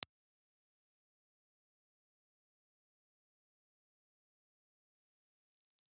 Impulse Response File:
Impluse Response file for the Sony FV300 dynamic microphone.
Sony_FV300_IR.wav